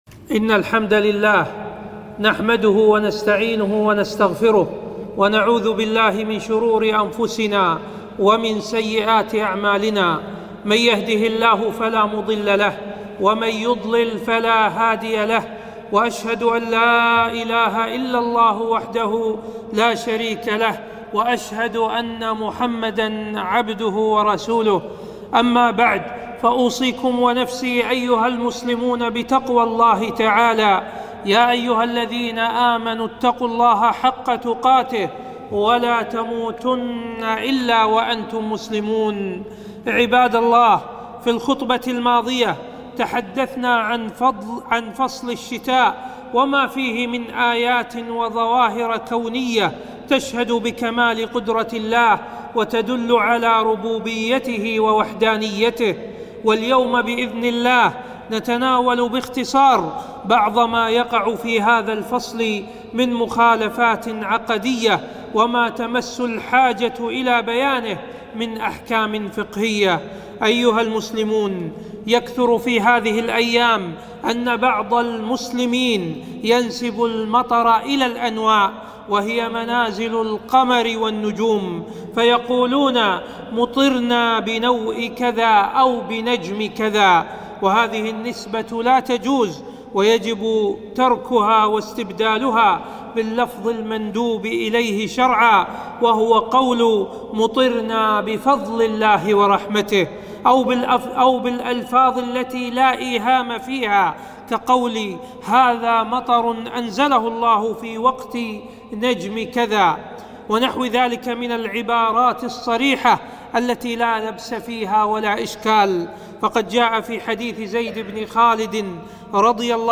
خطبة - أحكام الشتاء ج2